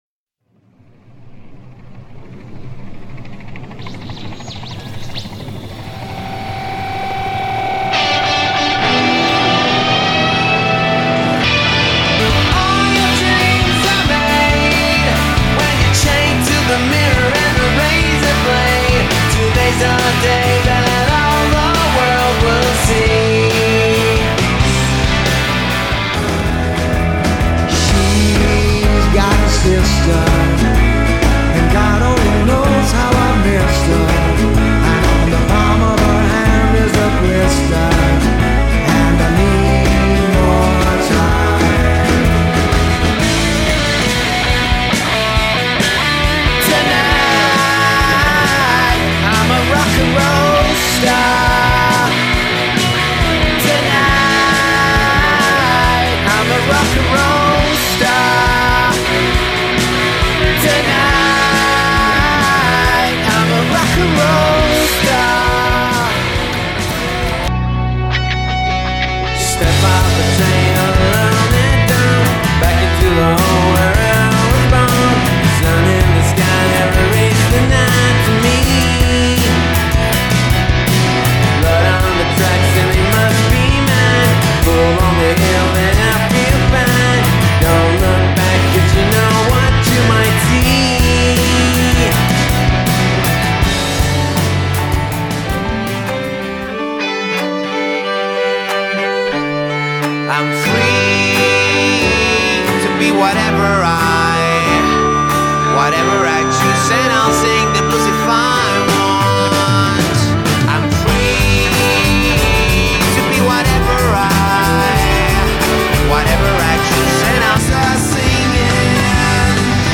Tribute Acts & Tribute Bands for hire
AUDIO MEDLEY MP3